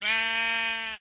sheep_say3.ogg